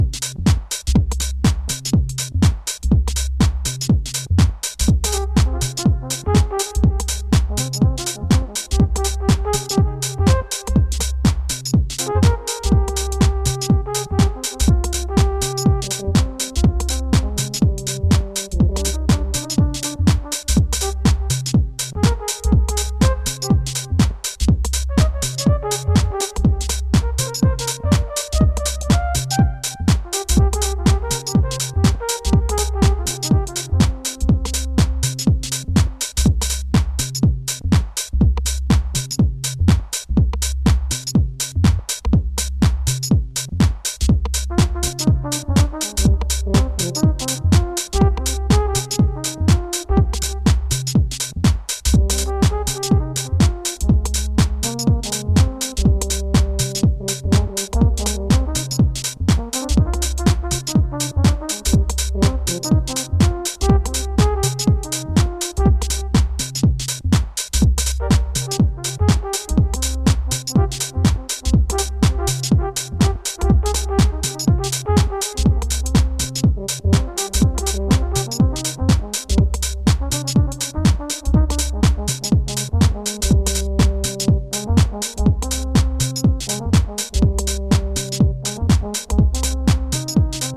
House
pure 1992 NYC Garage